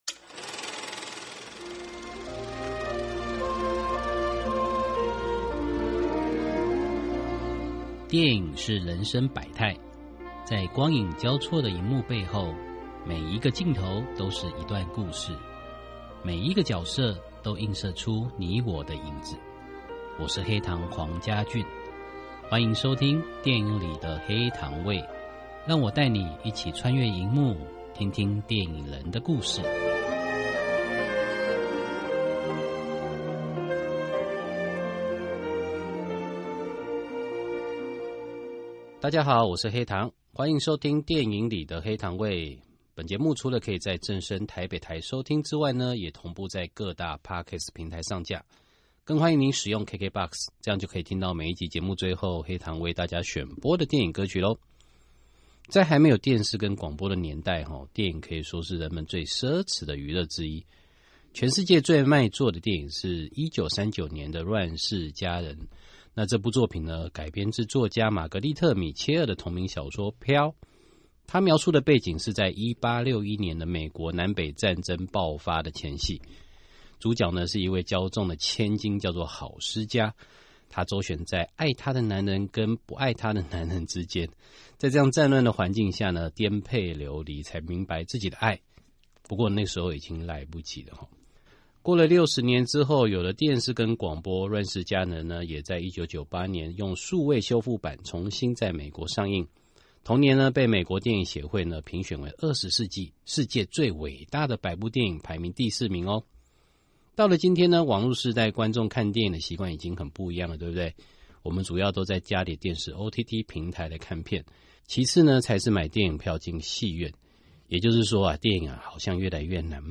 訪問大綱： 1.